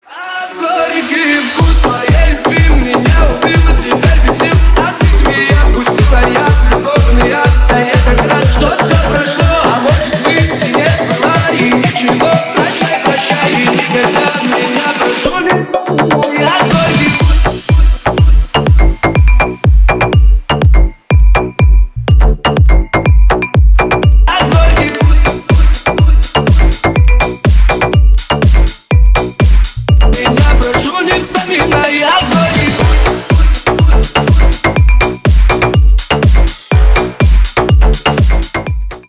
Ремикс
клубные # кавказские